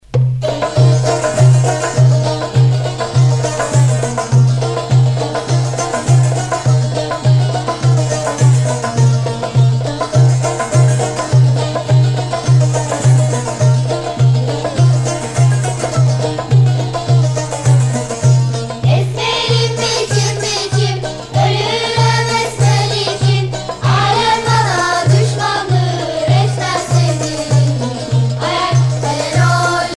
Festival folklorique de Matha 1980
Pièce musicale éditée